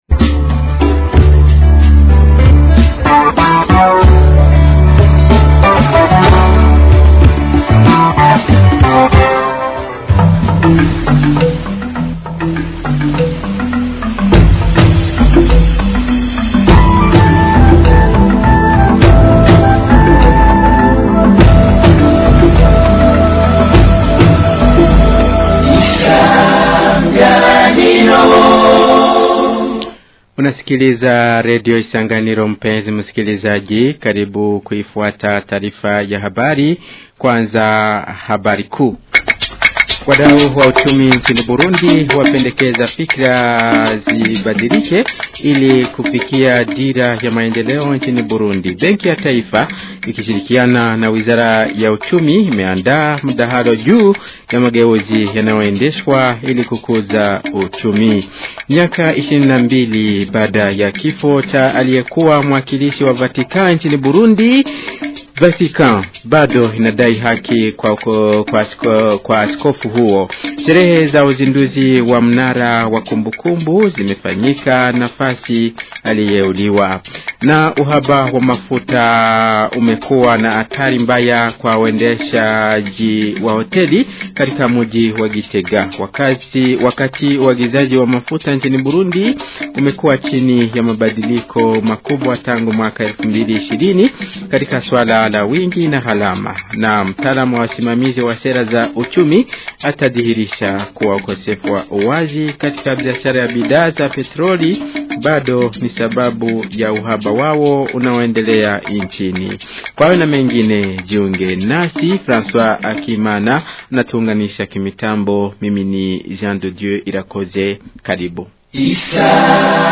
Taarifa ya habari ya tarehe 14 Agosti 2025